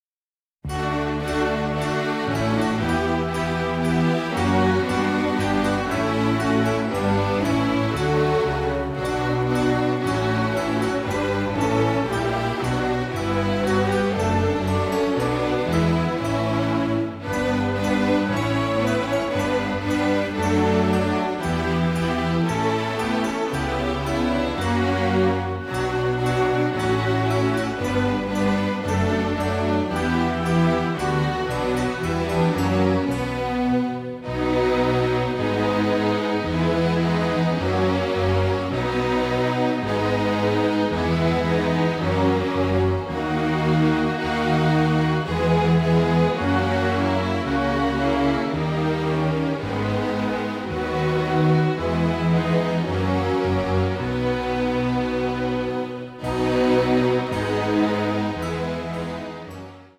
beautiful, nostalgic
radiantly passionate score